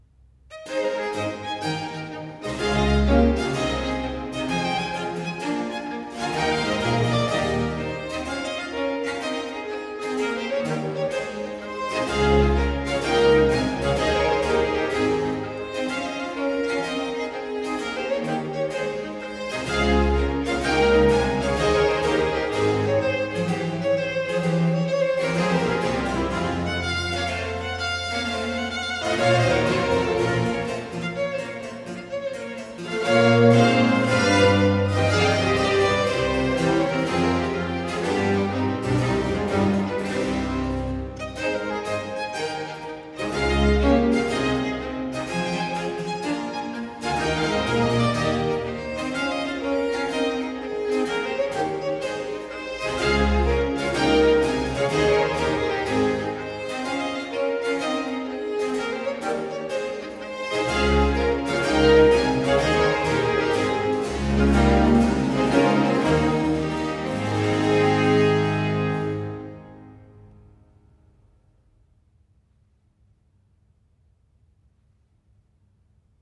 Sarabanda, largo